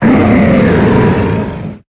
Cri de Félinferno dans Pokémon Épée et Bouclier.